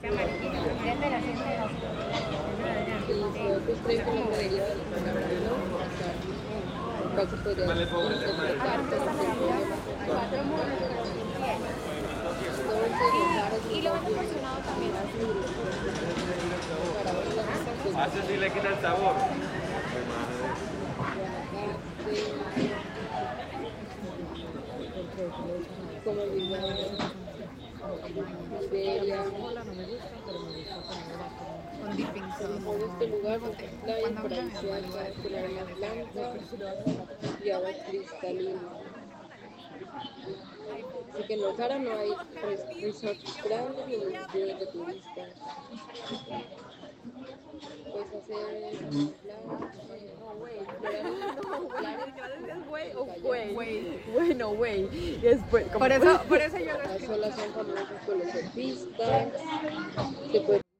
Sounds from home (elsewhere) are overlaid and geo-located along the canal.